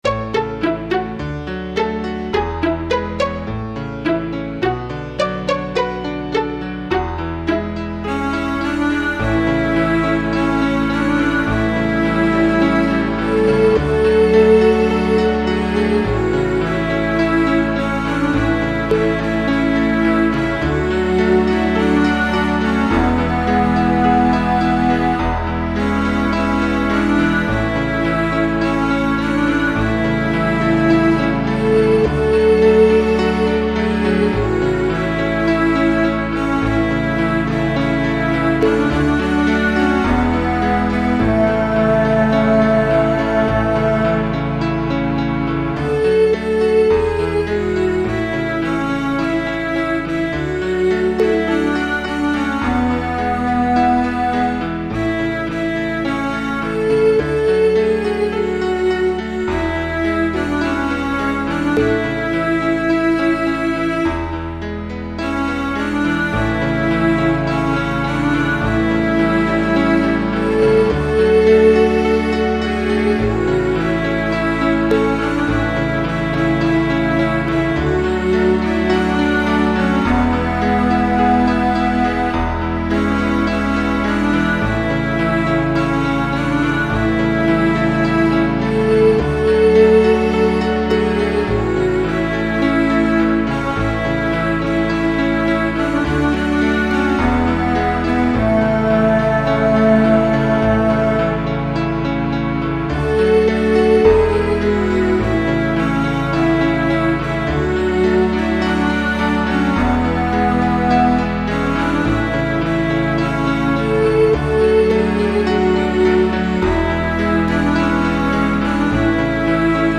Seekers-like children’s song
My backing is at 105 bpm.